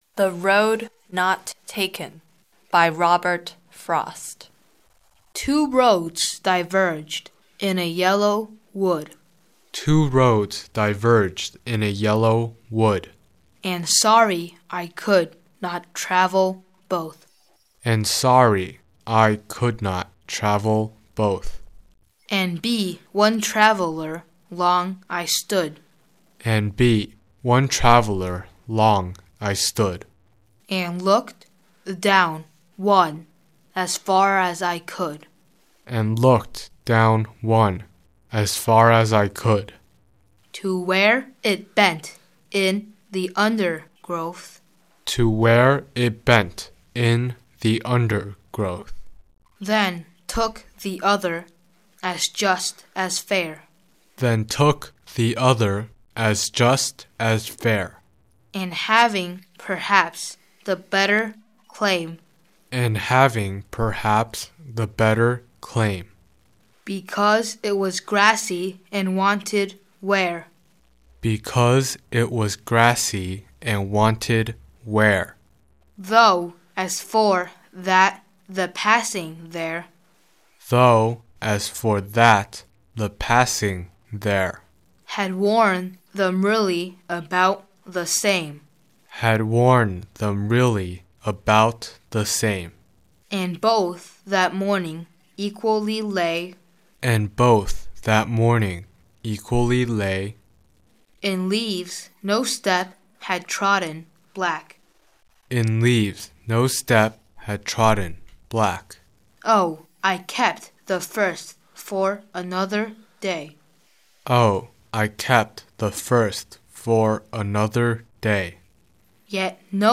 These CDs were carefully produced using different children’s voices to enhance clarity.